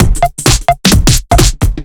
OTG_TripSwingMixD_130a.wav